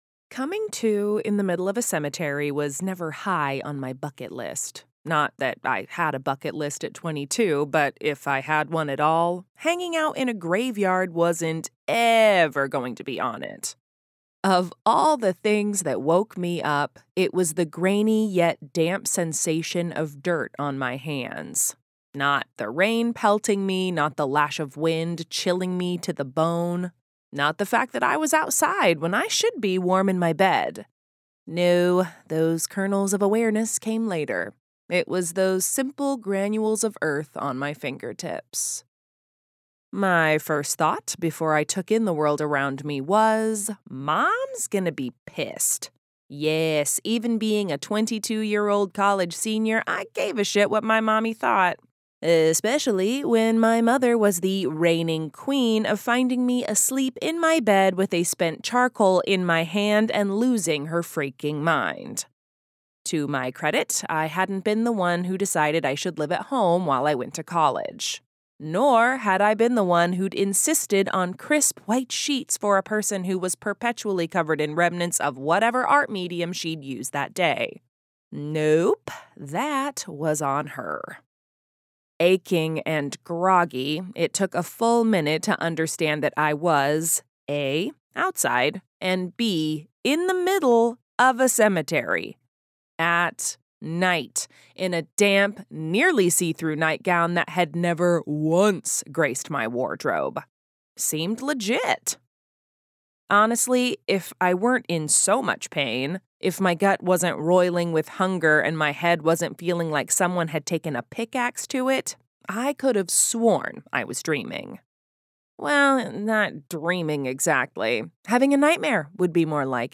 • Audiobook
NW_Retail_Sample_Mono_1.mp3